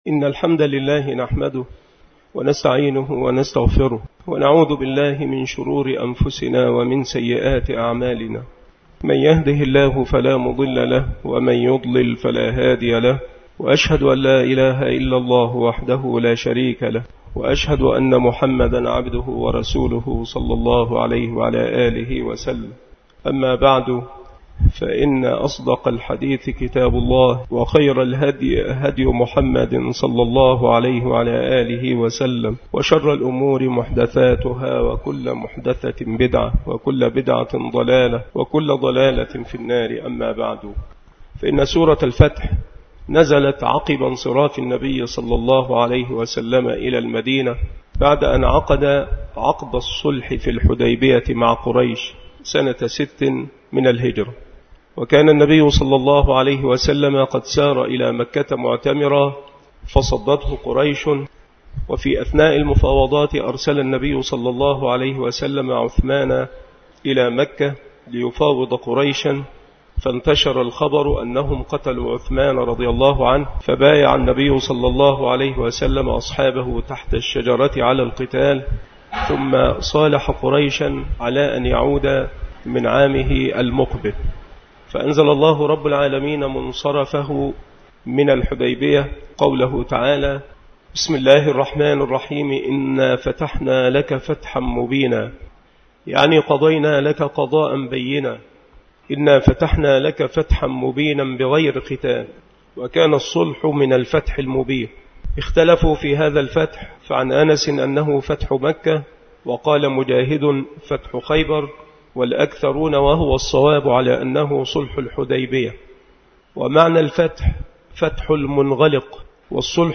التصنيف التفسير
مكان إلقاء هذه المحاضرة بالمسجد الشرقي بسبك الأحد - أشمون - محافظة المنوفية - مصر